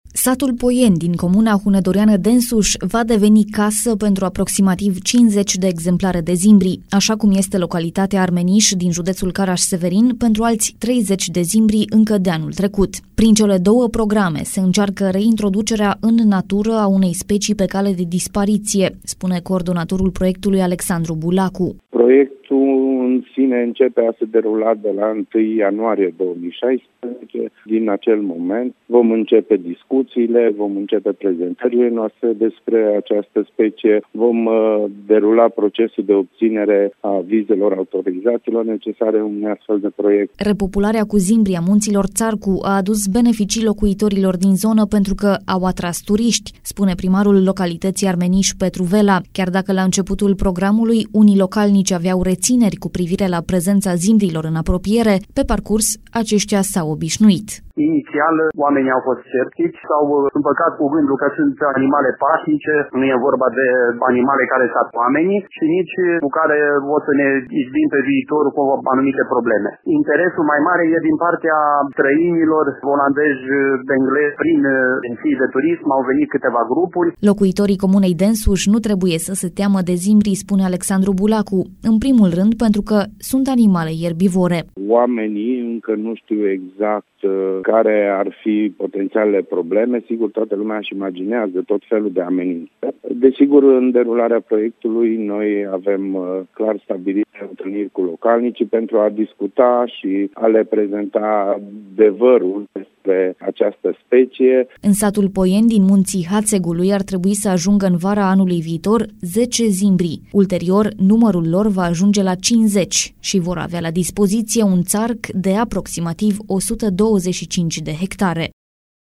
Repopularea cu zimbri a Munţilor Ţarcu a adus beneficii locuitorilor din zonă, pentru că au atras turişti, spune primarul localităţii Armeniş, Petru Vela.